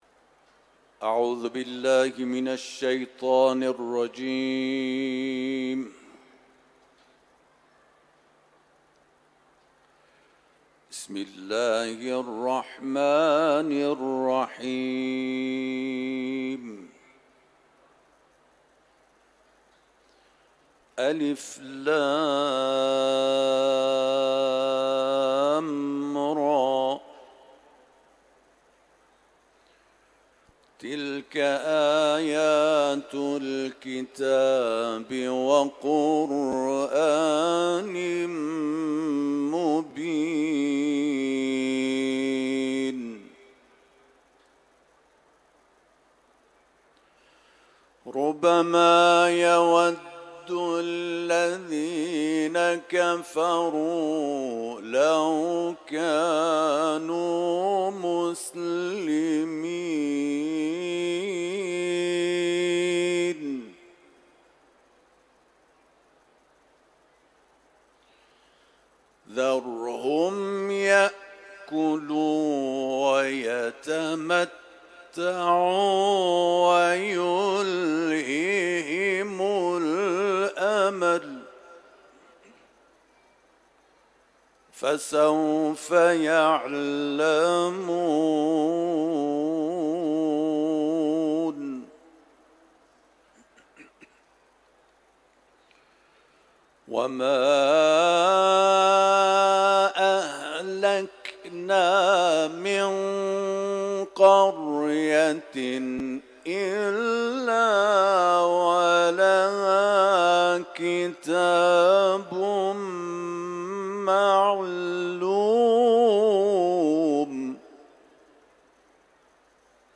صوت تلاوت آیات 1 تا 21 از سوره مبارکه «حجر»